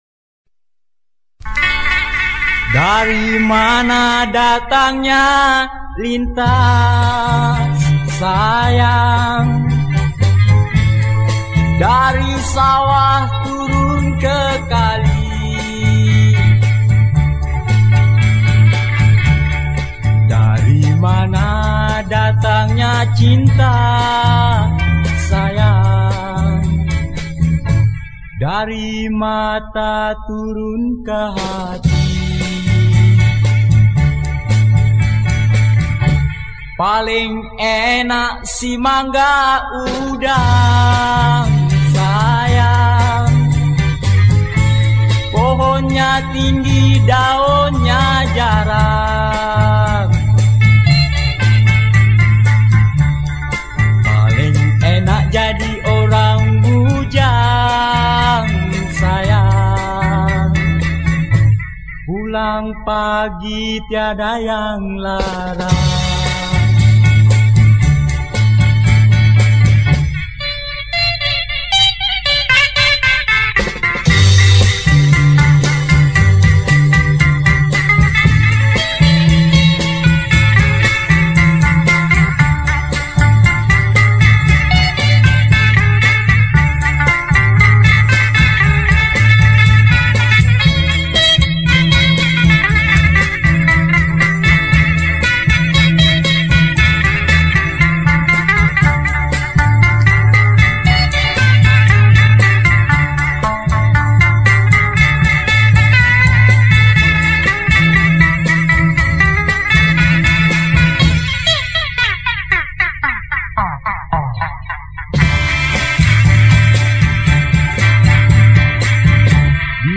Pop Yeh Yeh